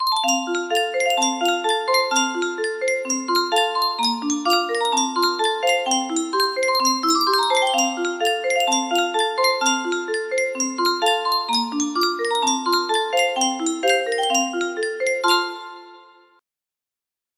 Yunsheng Custom Tune Music Box - Scotland the Brave music box melody
Full range 60